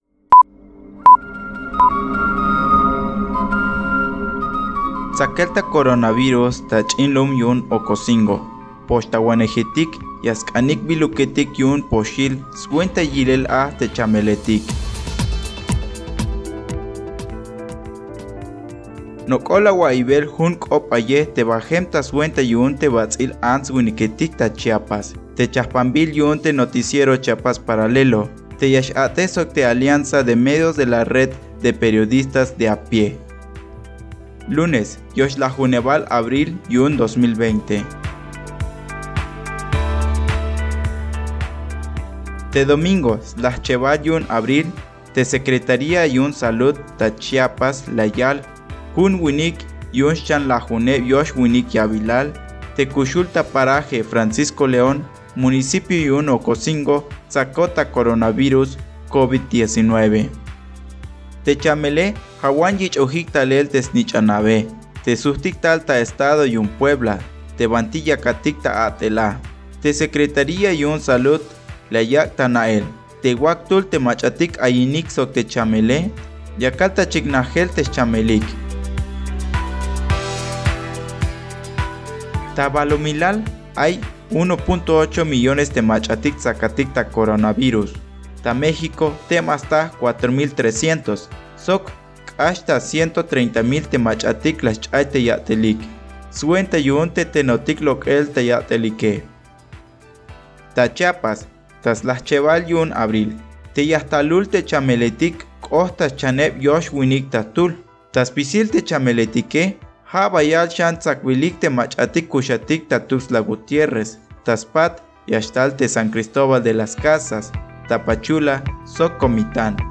Locución